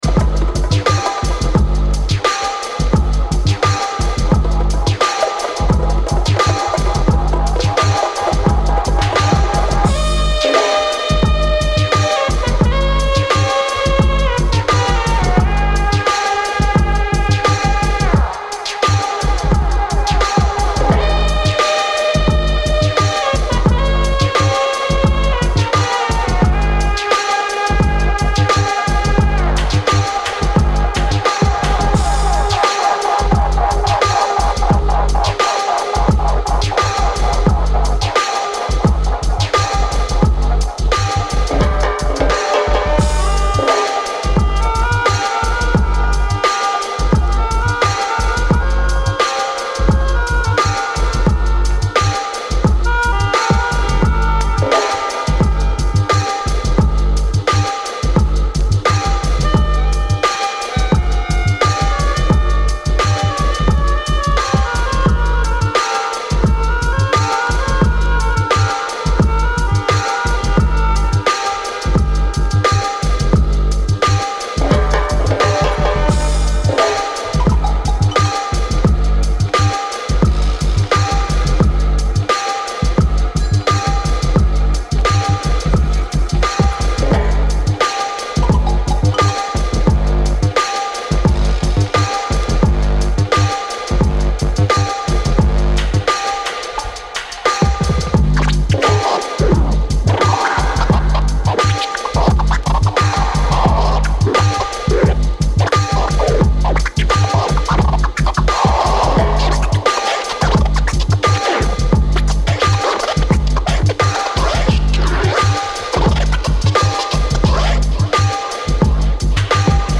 Killer Tripped-out beats